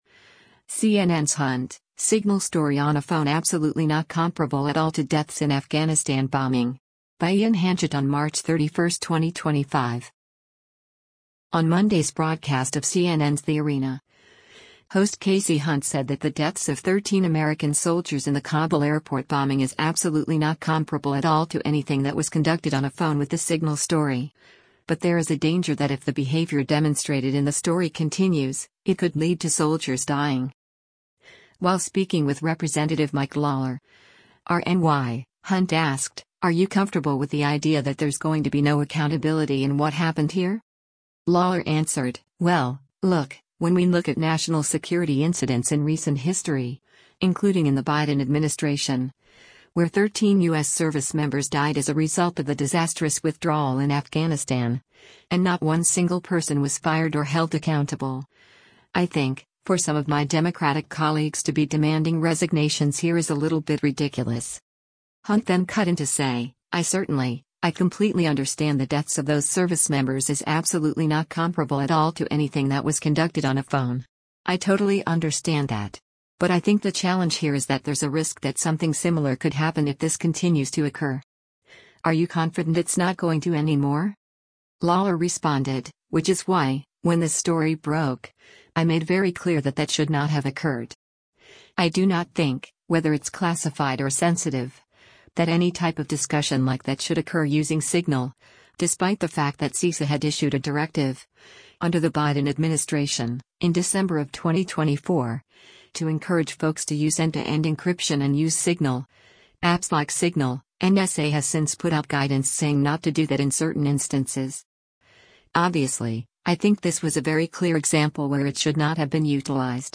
On Monday’s broadcast of CNN’s “The Arena,” host Kasie Hunt said that the deaths of 13 American soldiers in the Kabul airport bombing “is absolutely not comparable at all to anything that was conducted on a phone” with the Signal story, but there is a danger that if the behavior demonstrated in the story continues, it could lead to soldiers dying.
While speaking with Rep. Mike Lawler (R-NY), Hunt asked, “Are you comfortable with the idea that there’s going to be no accountability in what happened here?”